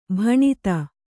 ♪ bhaṇita